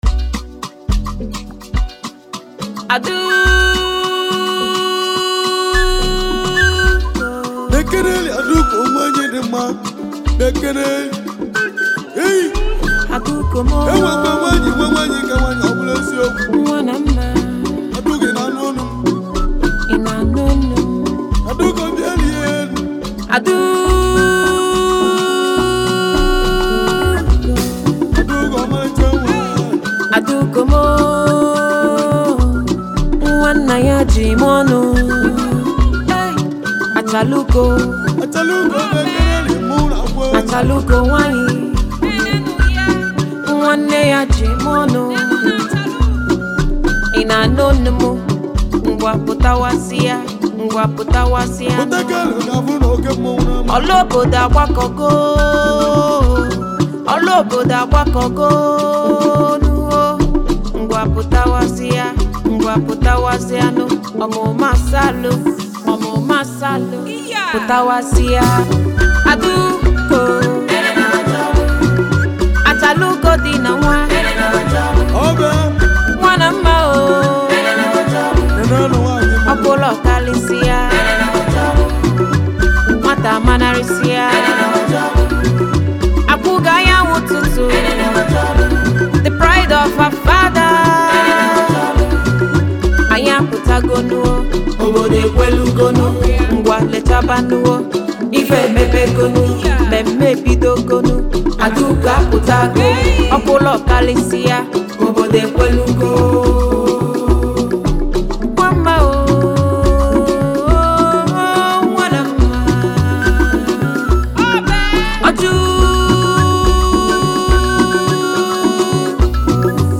With its captivating production and uplifting vibe